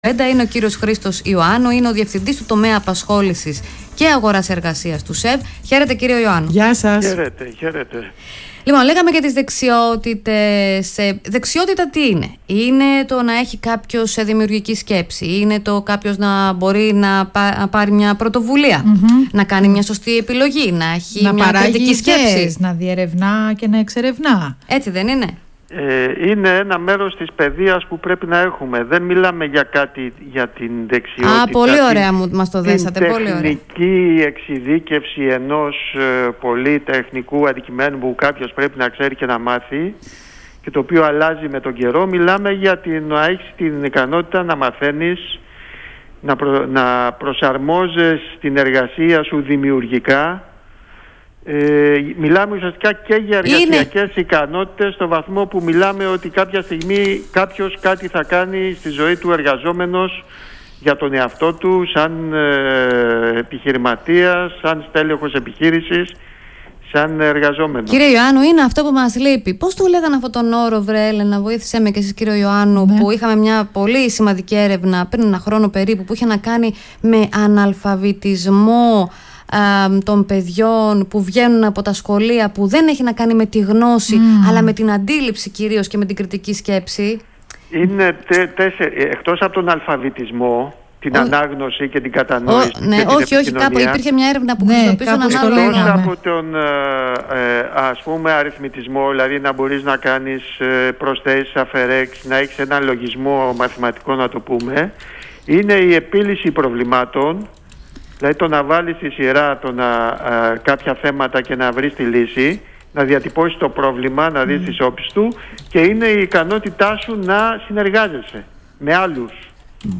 Συνέντευξη
στον Ρ/Σ ΠΡΩΤΟ ΠΡΟΓΡΑΜΜΑ της ΕΡT